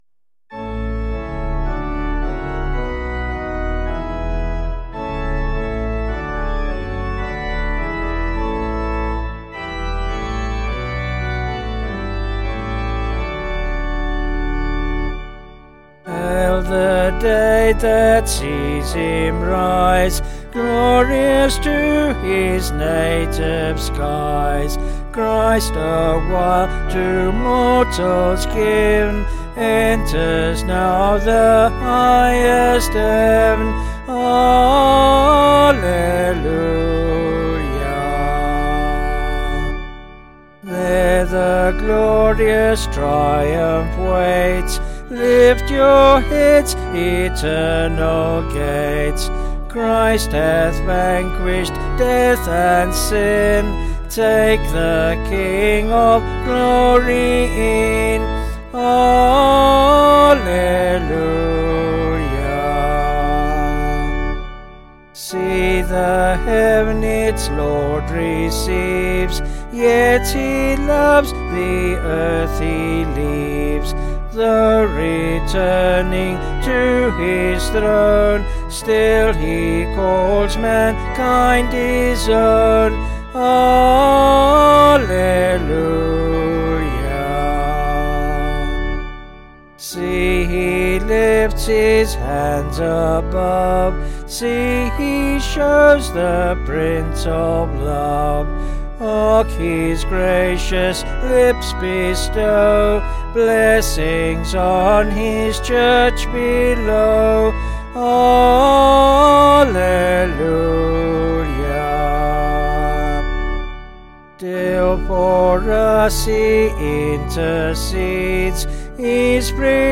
Vocals and Organ   705kb Sung Lyrics